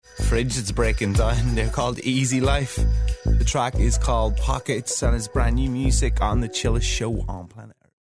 読み方
イージー・ライフ
BBC Radio 1 Phil Taggartの発音